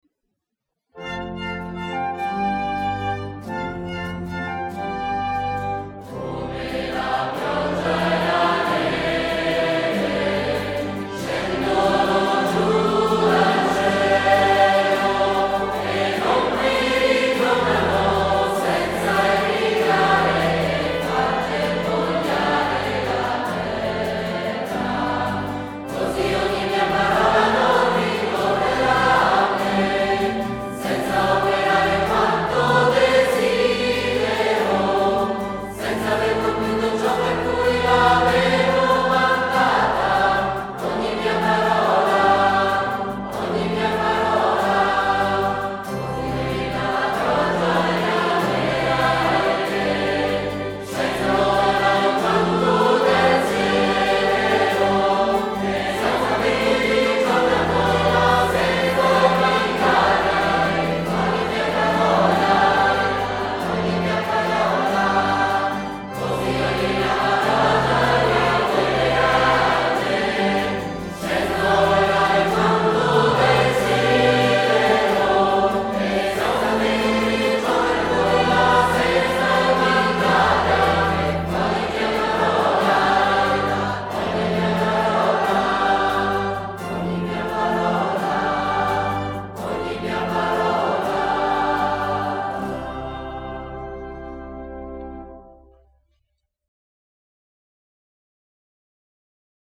Organo
Violino
Flauto traverso
Flauto dolce
Chitarra
Soprani
Contralti
Tenori
Bassi